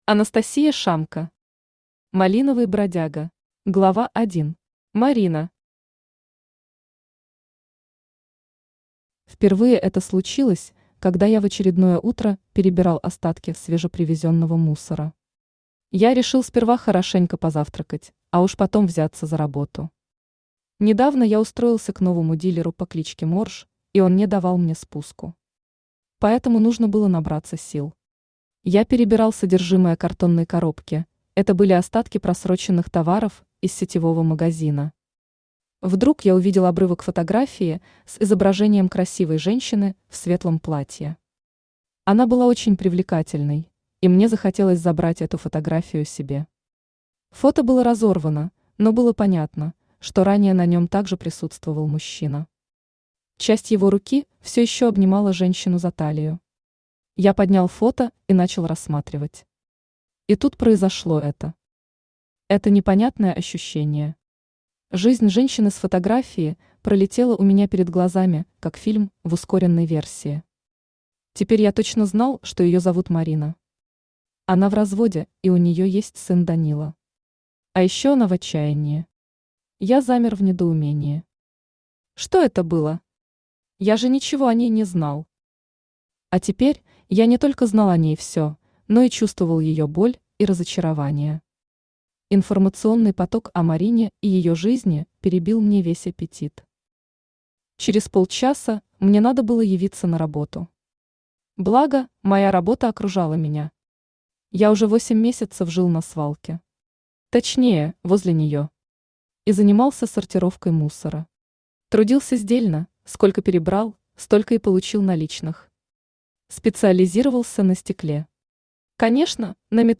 Аудиокнига Малиновый бродяга | Библиотека аудиокниг
Aудиокнига Малиновый бродяга Автор Анастасия Ивановна Шамко Читает аудиокнигу Авточтец ЛитРес.